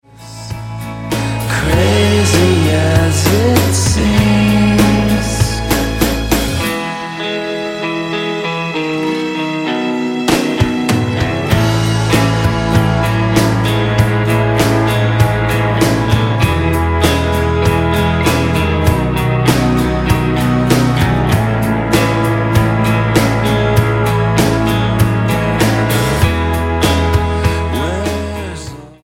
STYLE: Rock
serene synths